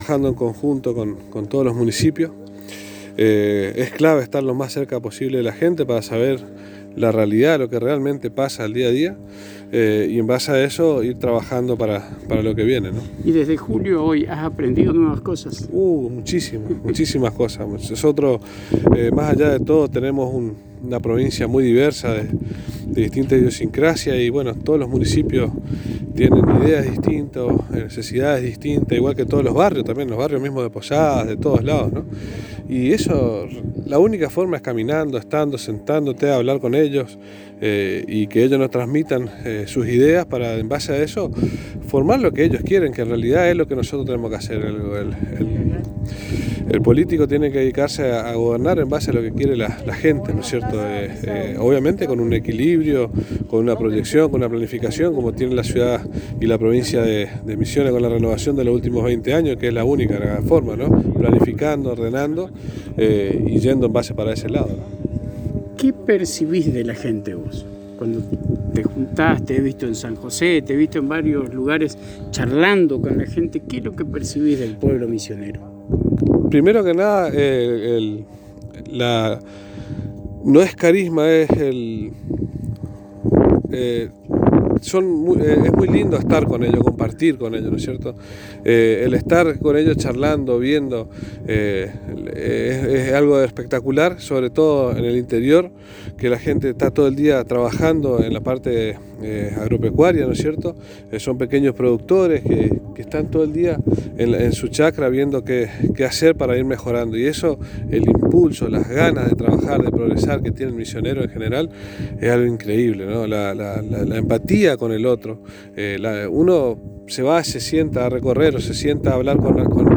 Daniel «Colo» Vancsik en diálogo exclusivo con la ANG manifestó que desde hace meses por mandato del Conductor Carlos Rovira está recorriendo la Provincia con la consigna de escuchar al misionero y misionera de cada barrio, cada chacra, para tener en cuenta cuáles son las necesidades de los misioneros en su lugar donde habita, donde trabaja, para luego realizar esos pedidos en acciones de gobierno organizadas pensadas y así tomar medidas que beneficien al pueblo.